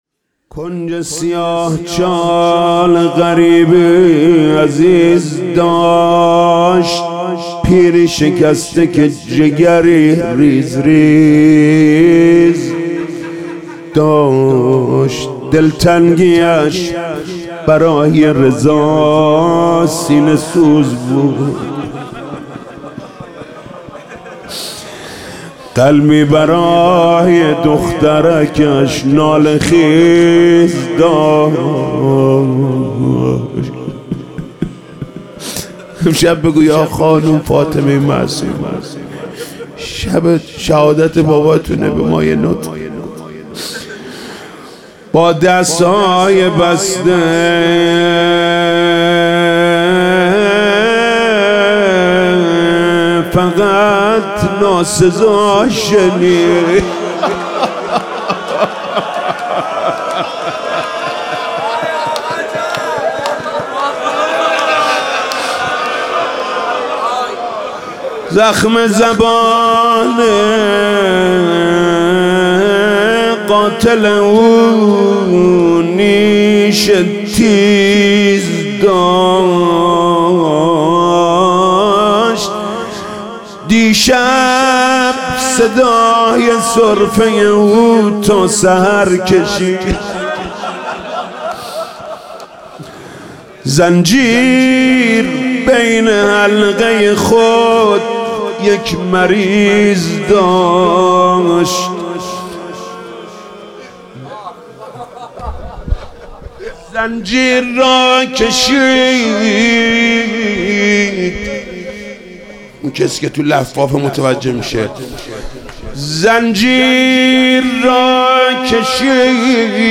روضه: کنج سیاه چال غریبی عزیز داشت